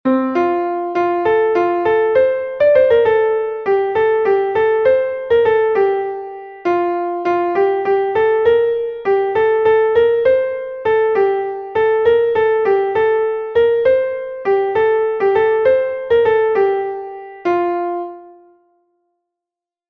Melodie: Volksweise